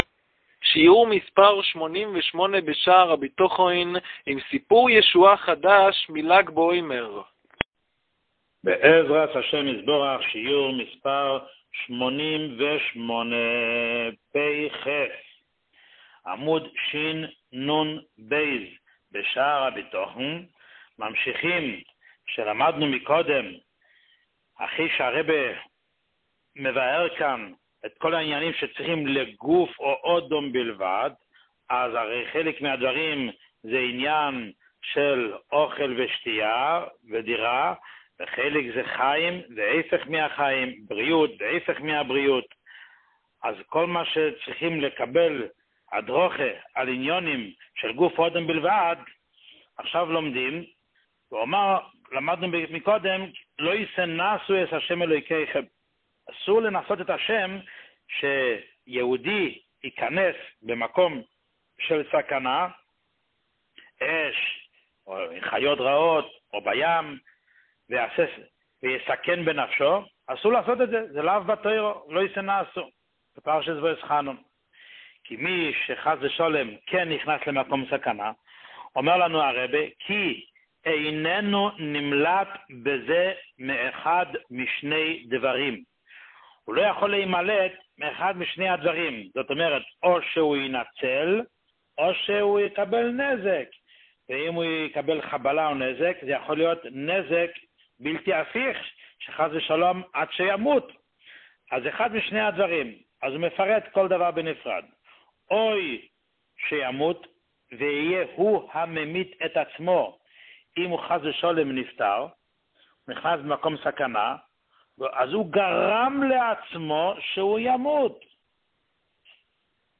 שיעור 88